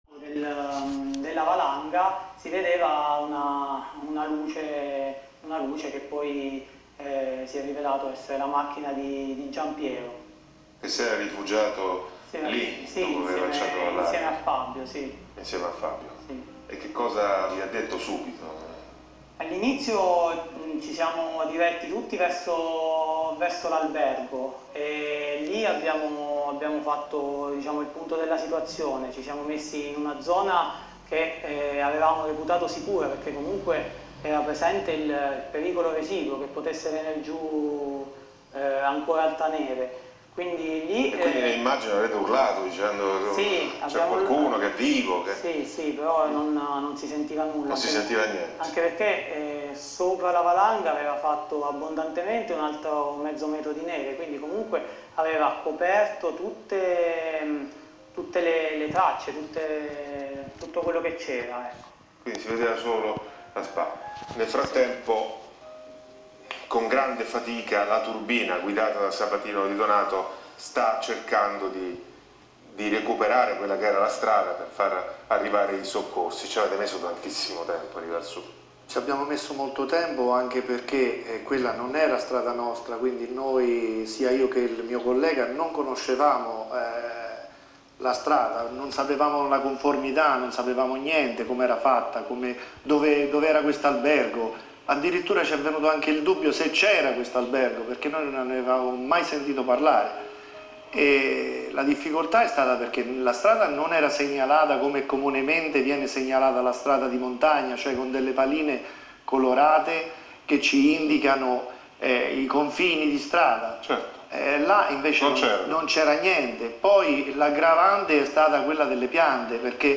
del quale è possibile ascoltare le dichiarazioni rilasciate nella odierna intervista realizzata da Marco Liorni per “La Vita in Diretta”